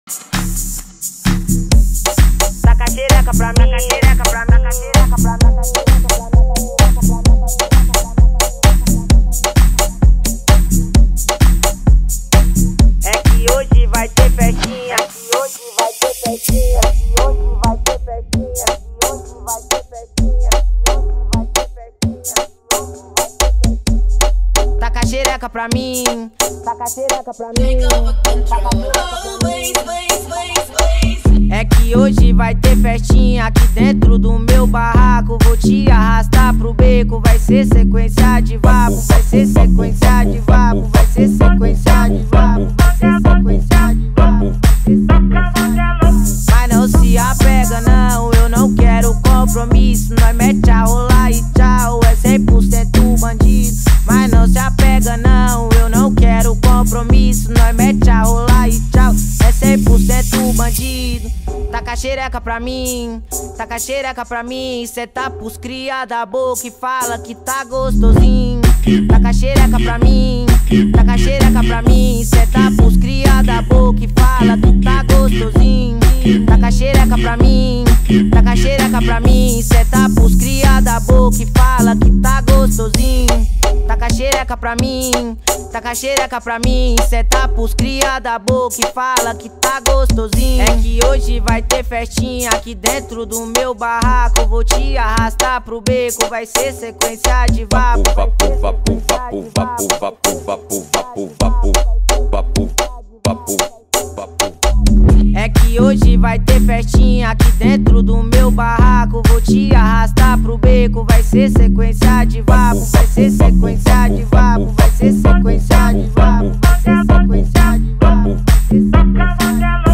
BPM0-130
Audio QualityPerfect (Low Quality)